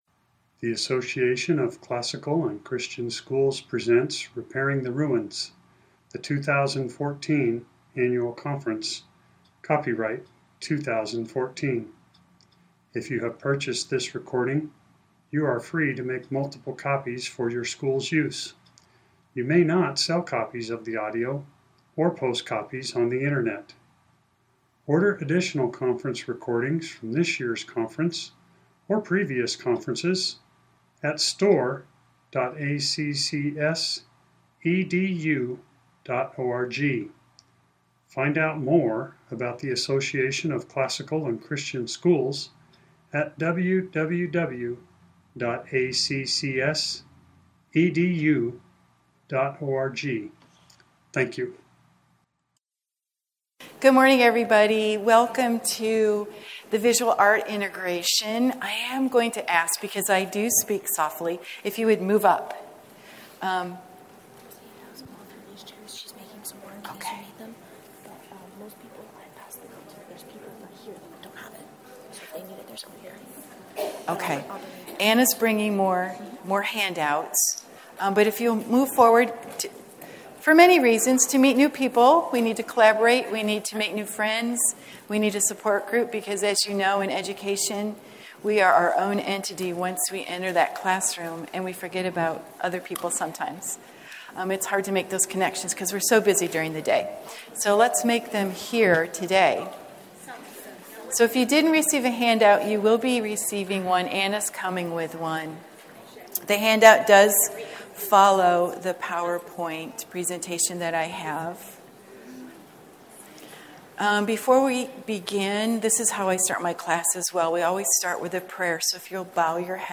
2014 Workshop Talk | 0:56:32 | All Grade Levels, Art & Music